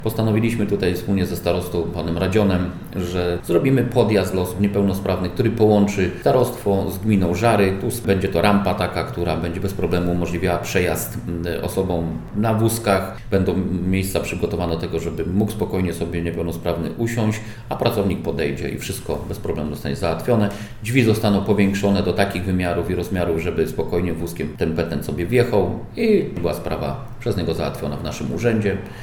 – Zleciliśmy już przygotowanie dokumentacji technicznej na inwestycję – mówi wójt żarskiej gminy, Leszek Mrożek: